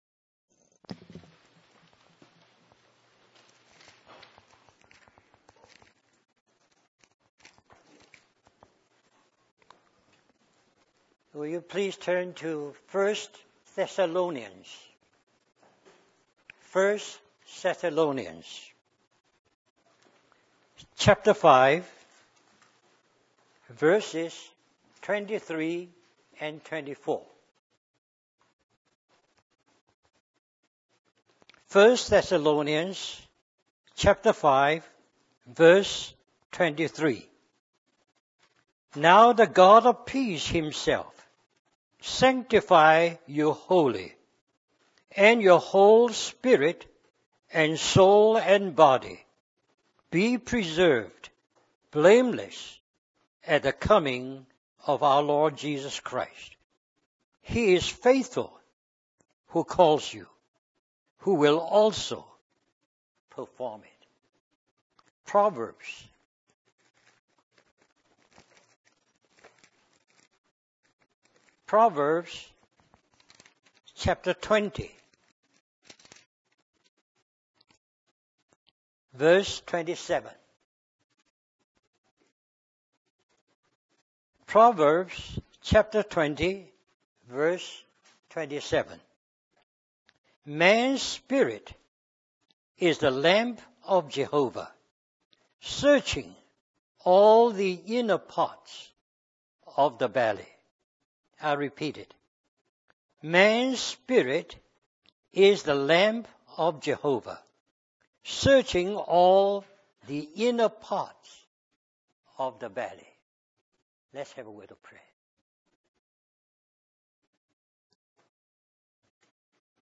In this sermon, the preacher emphasizes the importance of our spirit and the need to pay attention to it. He explains that the main functions of our spirit are communion, intuition, and conscience.